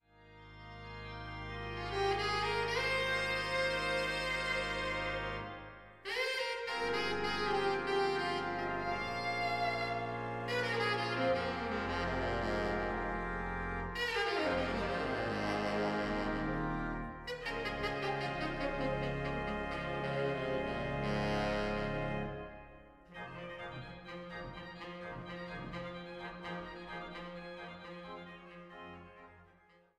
Orgel
Saxophon